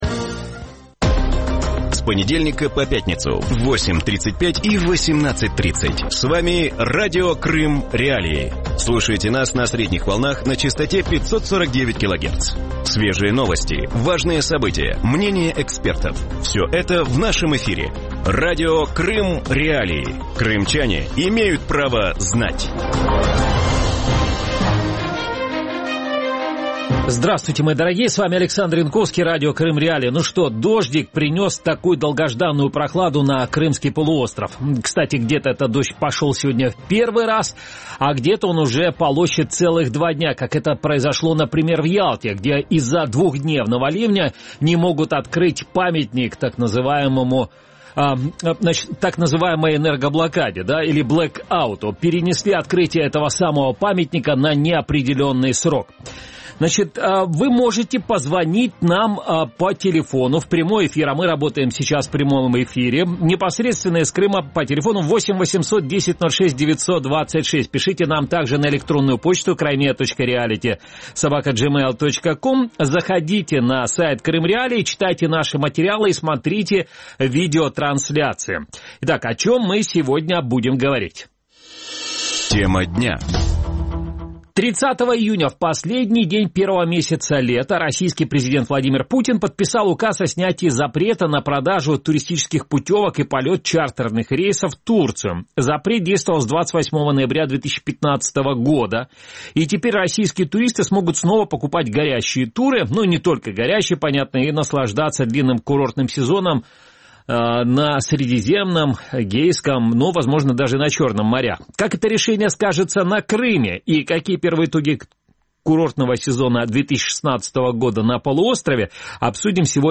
В вечернем эфире Радио Крым.Реалии обсуждают отмену запрета на продажу туристических путевок в Турцию и перспективы крымского туристического сезона. Какие курорты выбирают россияне и что ожидает туристический бизнес?